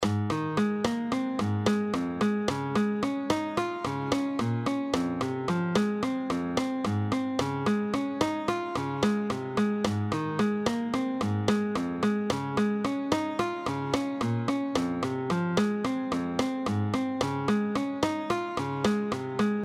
Example 5: 9/8 Count